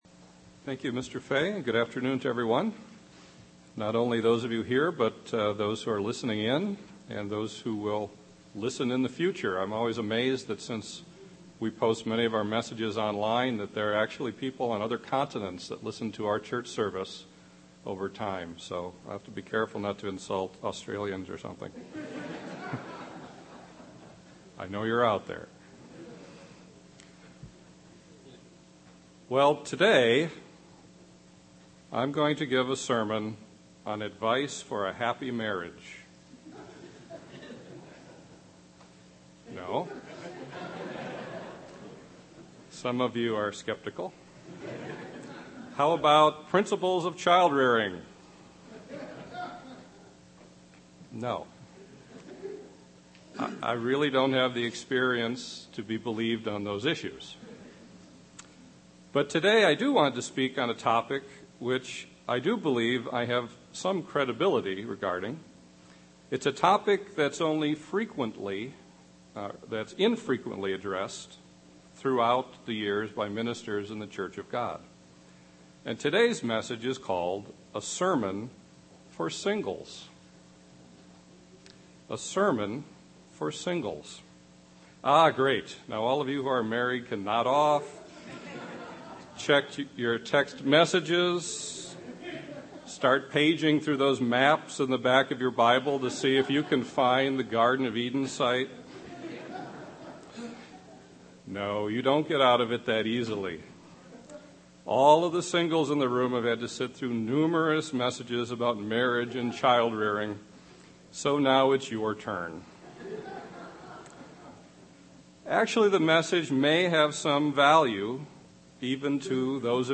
Sermon for Singles
Given in Chicago, IL